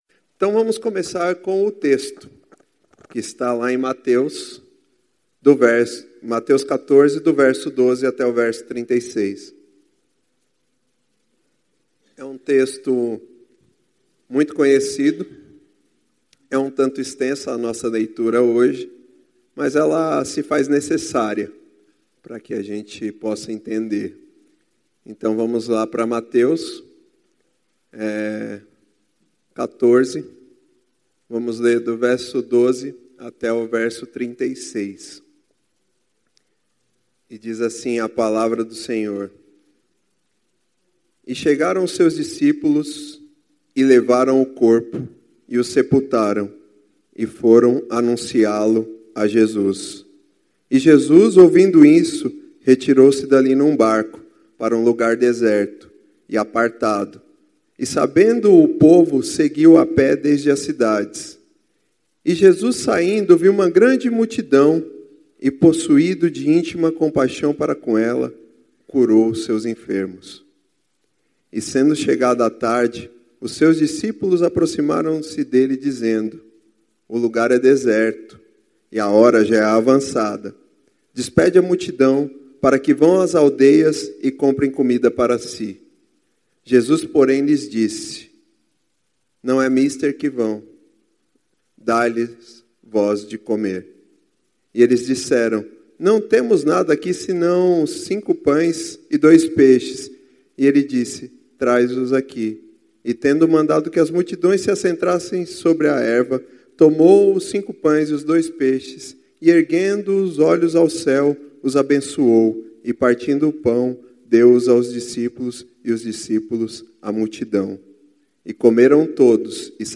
Mensagem do dia 18 de Agosto de 2019.